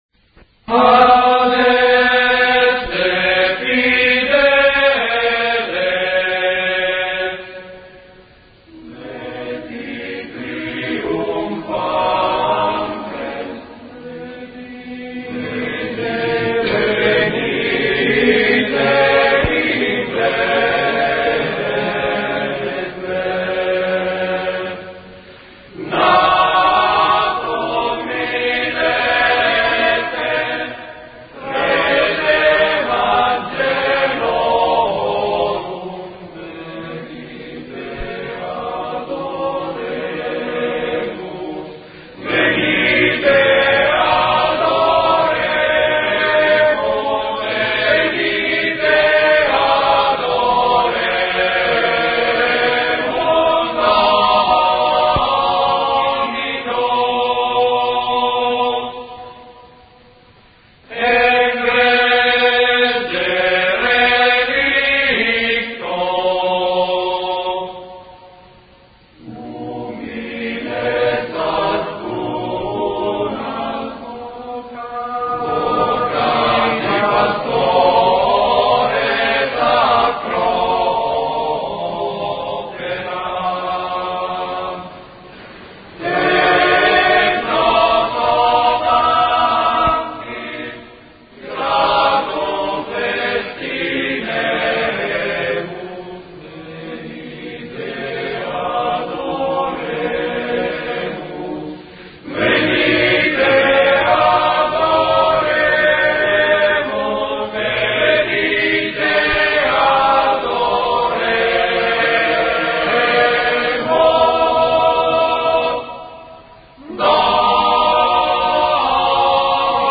Adeste fideles [ voci virili ] Adeste fideles, laeti triumphantes venite, venite in Bethleem, natum videte regem angelorum.